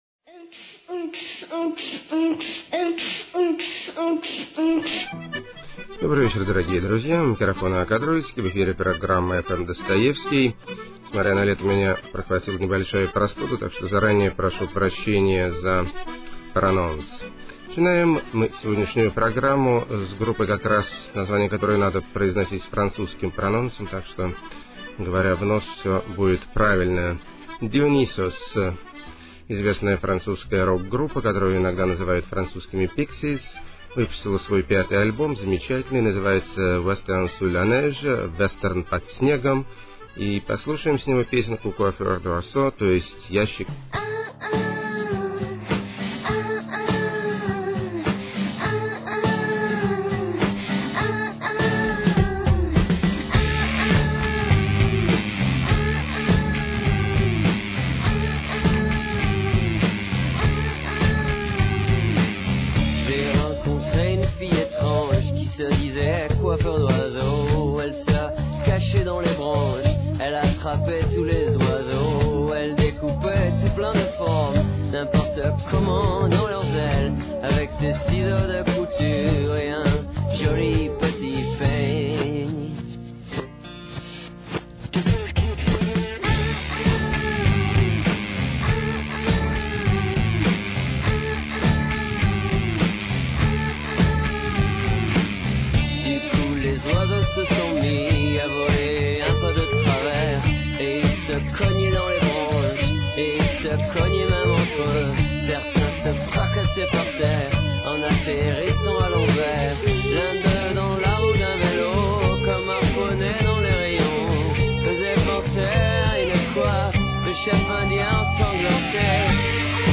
Народные Гитарные Виртуозы Рвут Струны И Души.
Духоподъёмный Евро-китч.
Американская Депресуха С Английской Элегантностью.
Шикарные Дамские Баллады.
Эпилептический Рок/блюз.